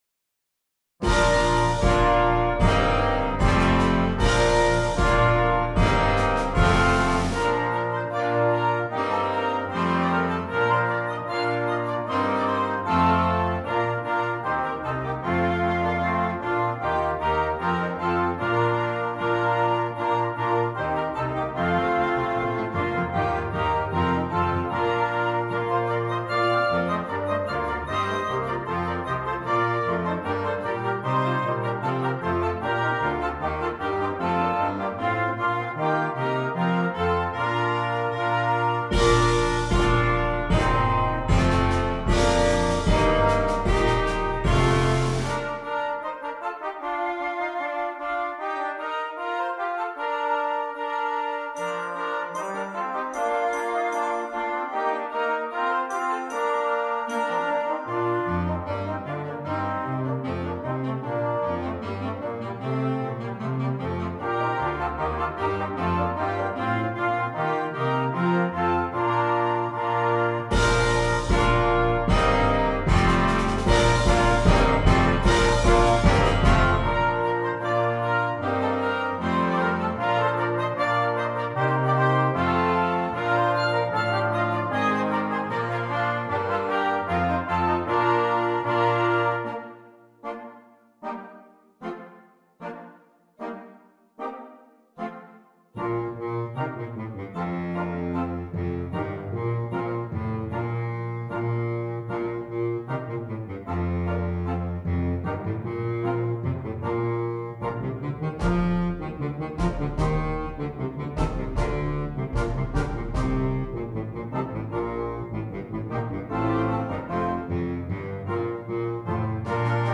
Flexible Band Series
Traditional Carol